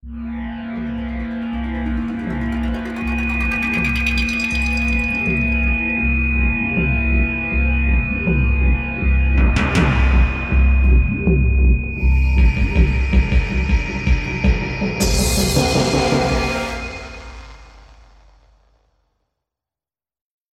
In the final version of this music sketch, I added in a few sound effects and audio effects to give the track a more sci-fi feel. To minimize the horns at the end, I dropped a noisy rocket effect and blended it with the final accents to transition to the next scene where the alien abducts our hero:
Final score based on improvisation